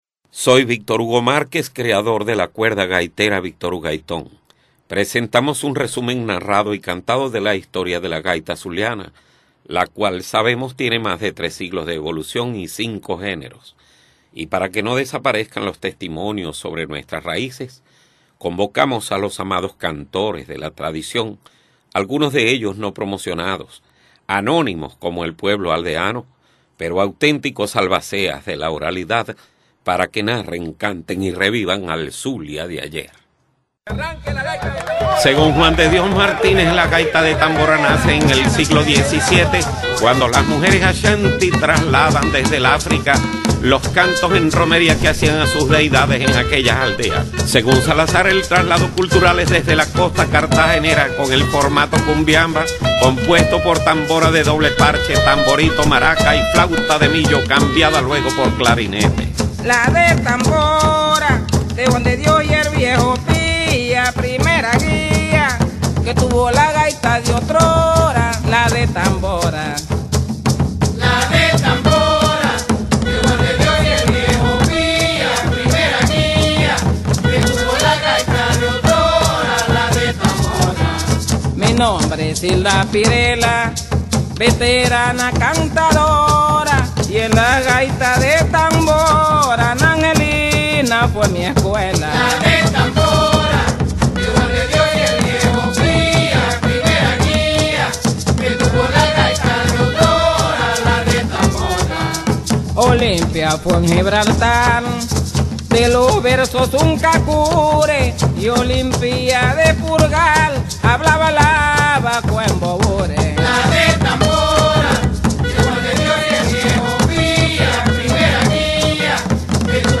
Gaita de Tambora Con una estructura r�tmica binaria de 2X4, se le denomina as� por ser la tambora de doble parche el principal instrumento musical. Su bater�a es muy parecida a la de la cumbia o gaita ancestral de la costa atl�ntica de Colombia y la del Tamborito de Panam�, a saber: tambora de doble parche, tamborito, un maracon y un clarinete que sustituye la vieja ca�a de millo.
catedragaitadetambora.mp3